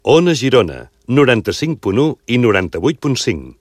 Indicatiu i freqüències de l'emissora